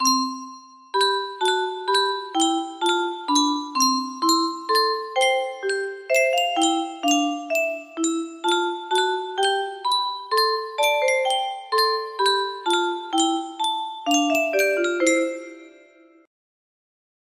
Clone of Yunsheng Spieluhr - Vom Himmel hoch da komm ich her music box melody